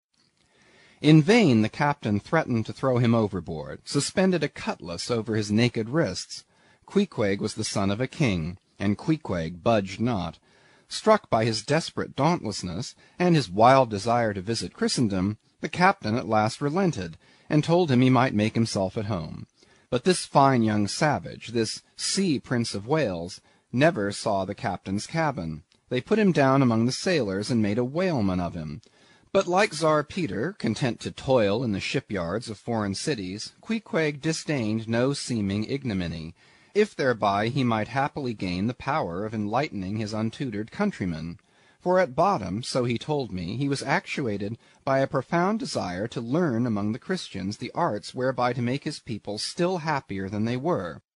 英语听书《白鲸记》第260期 听力文件下载—在线英语听力室